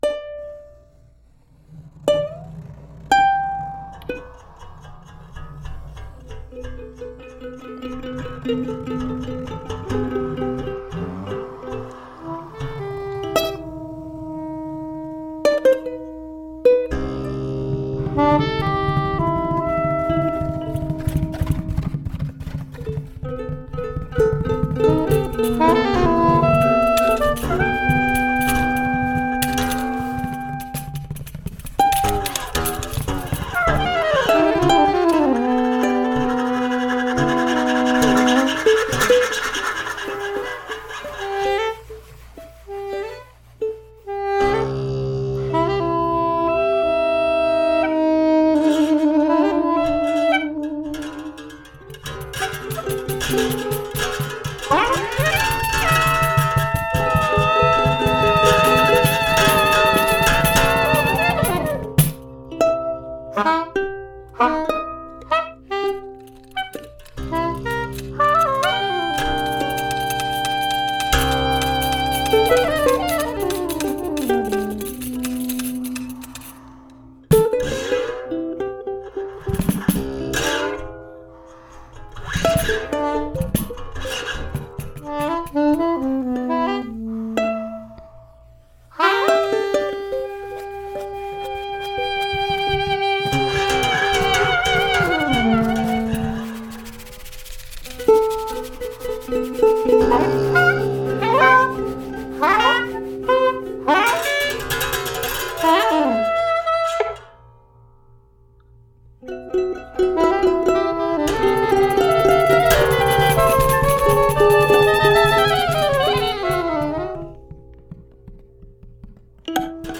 All that music inspired this piece, performed on ukulele, diddley bow, pandeiro, and two tracks of soprano saxophone.